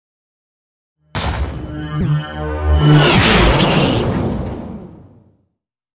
Double hit with whoosh sci fi
Sound Effects
Atonal
futuristic
woosh to hit